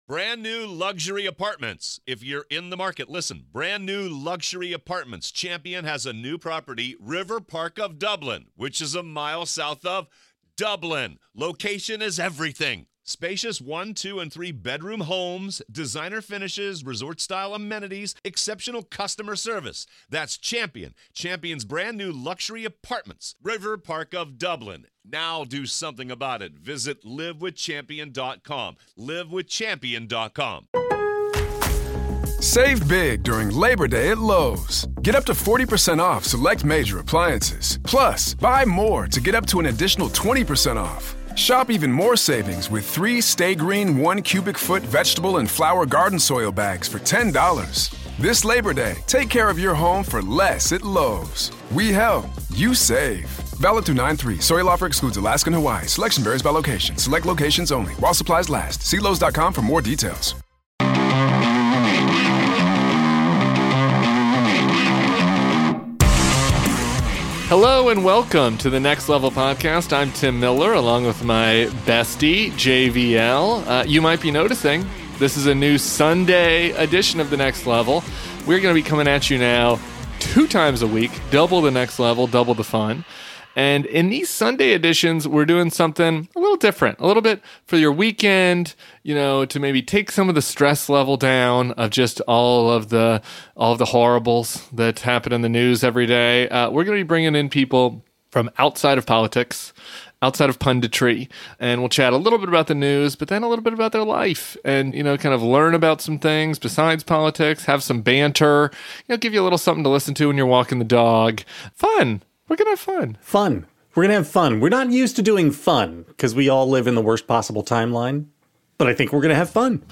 Politics, News, News Commentary